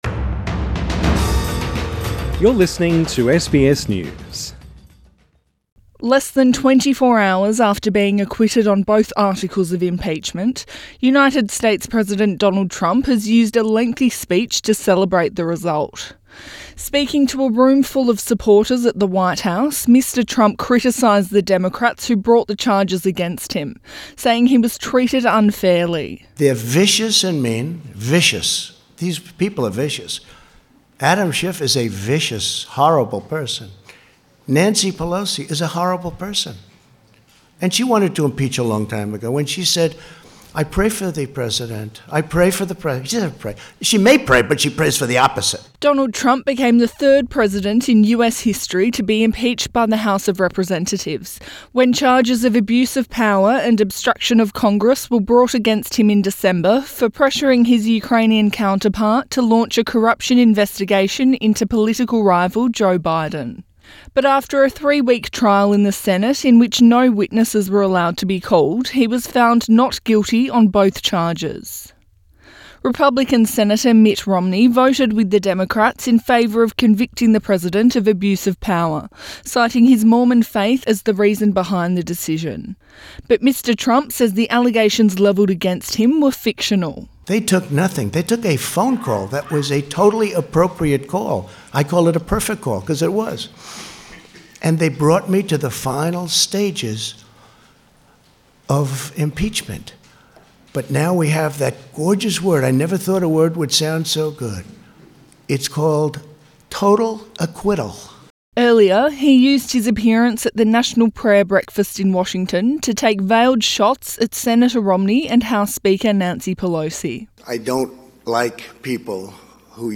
US President Donald Trump has used a speech at the White House to celebrate his impeachment acquittal and criticise the Democrats who brought the charges against him.